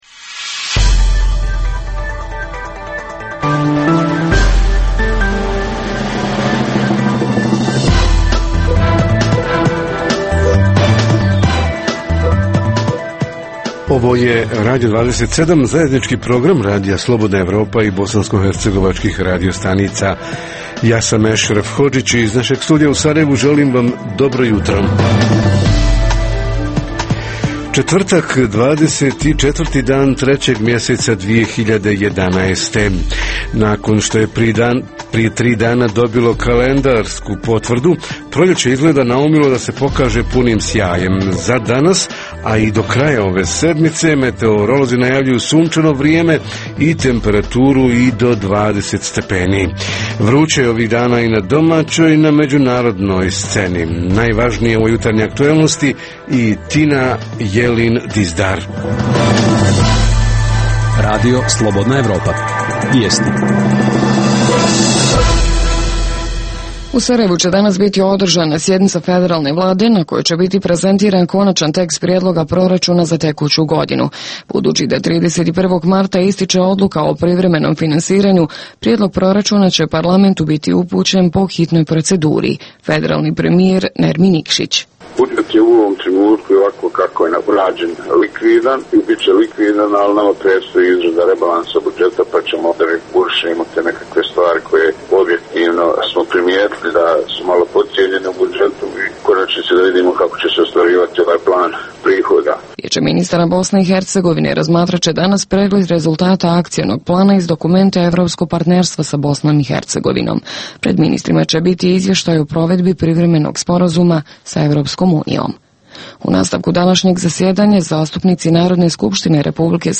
- Redovni sadržaji jutarnjeg programa za BiH su i vijesti i muzika.